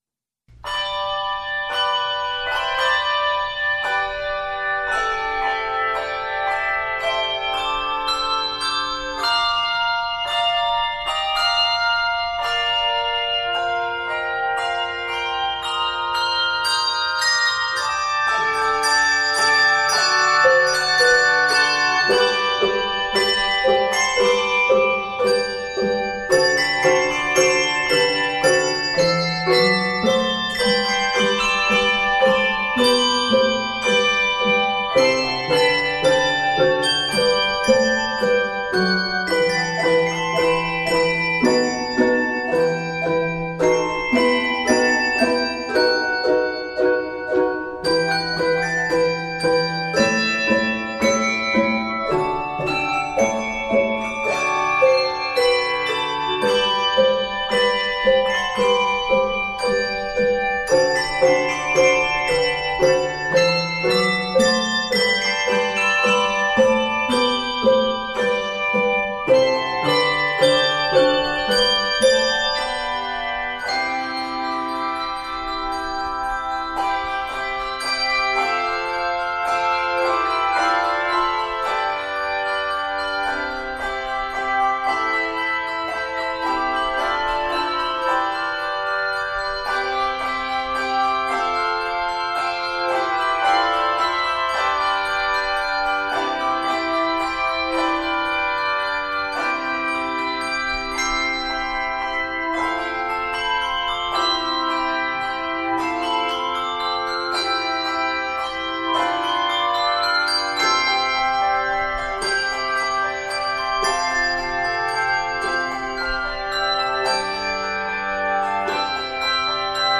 joyous arrangement
Begins in the key of G Major